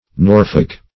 Norfolk \Nor"folk\, n.